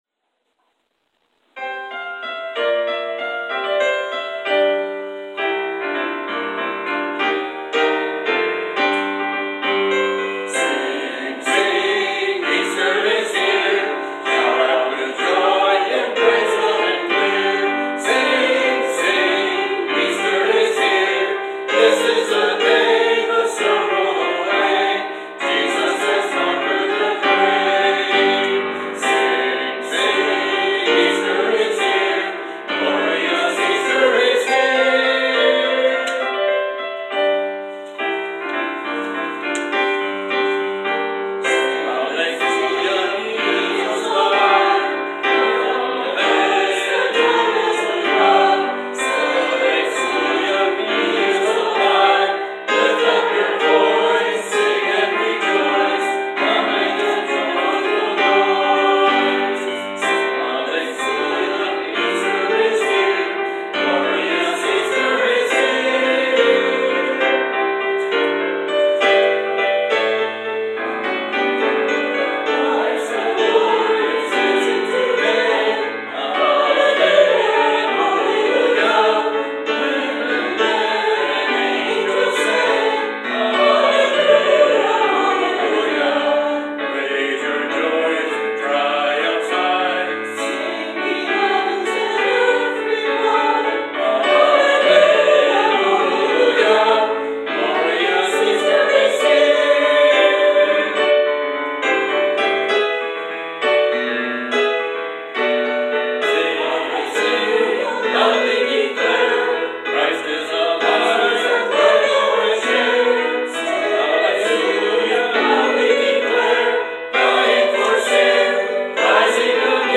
Choir prelude: “Easter is Here” – Jon Paige